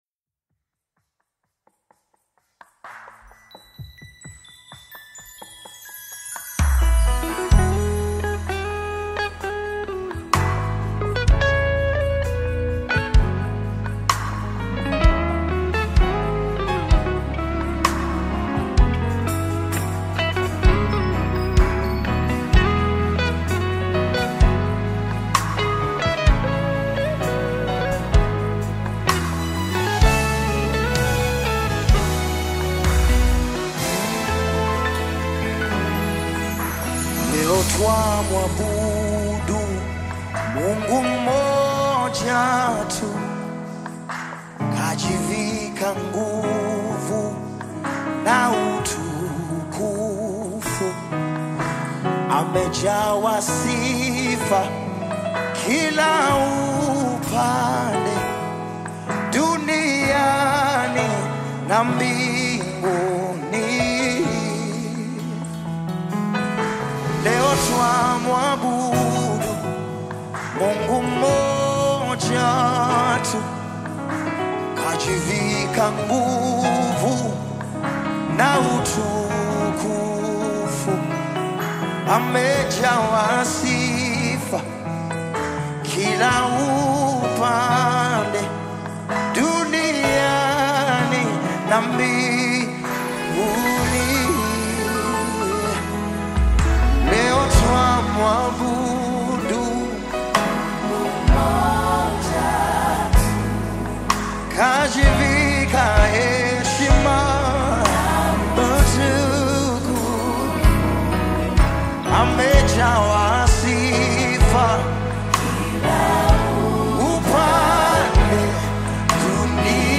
Nyimbo za Dini Praise music
Praise Gospel music track